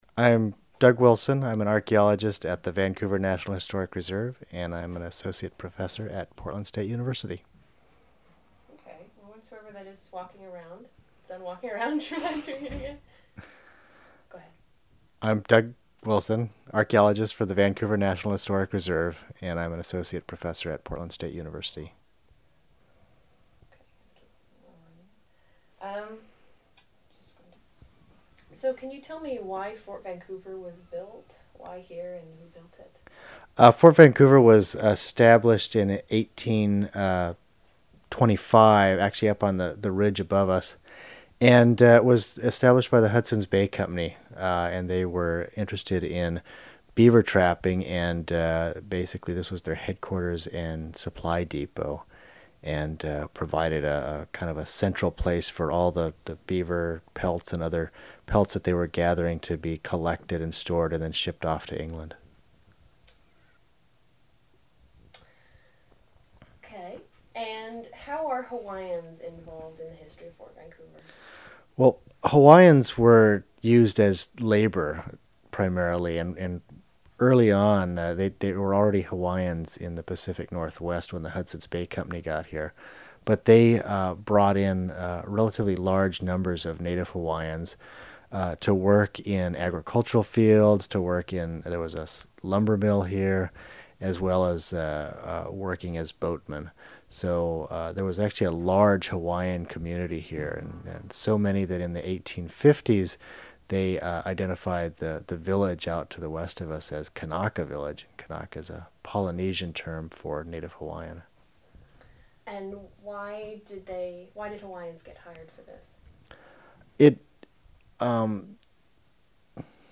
Hawaiians in the Fur Trade Interview